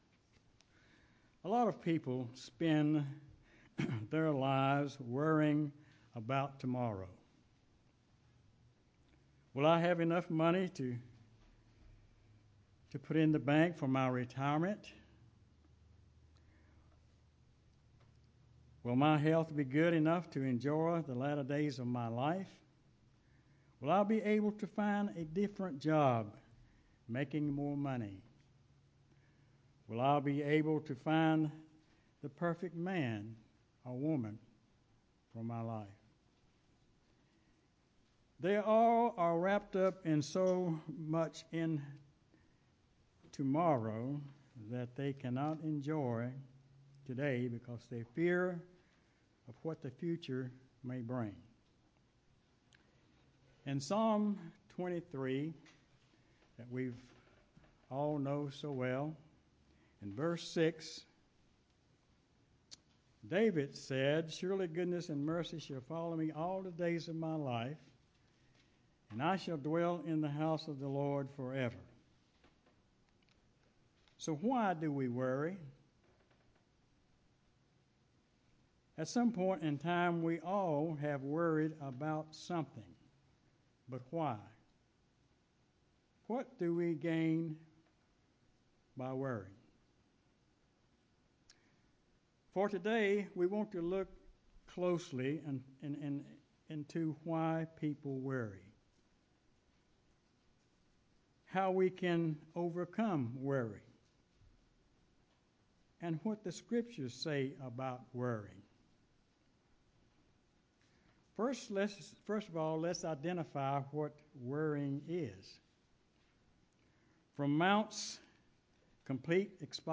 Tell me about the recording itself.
Given in Greensboro, NC